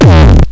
home *** CD-ROM | disk | FTP | other *** search / The Best of Mecomp Multimedia 1 / Mecomp-CD.iso / samples / bdrum-samples / total-dist.
total-dist.mp3